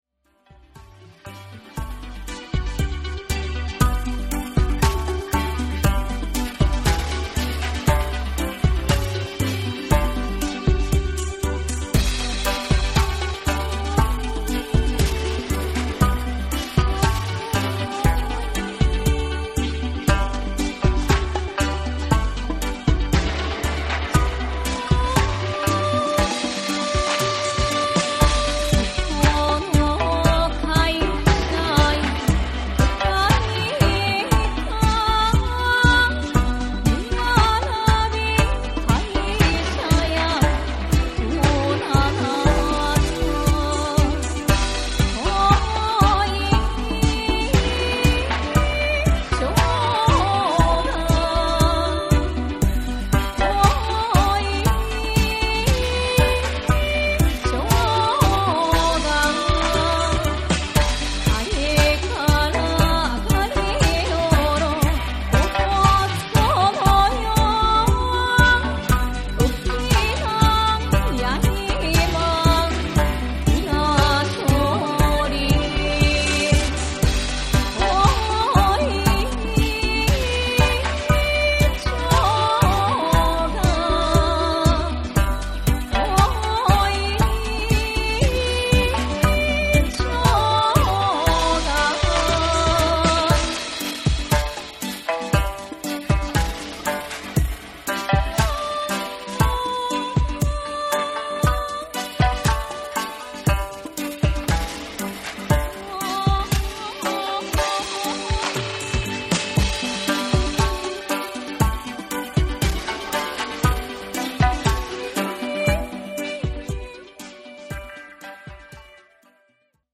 スローモー・アシッド・テクノと三線がビルドアップする
ゆったりとしたエレクトロ・ダブ
JAPANESE / ORGANIC GROOVE / NEW RELEASE(新譜)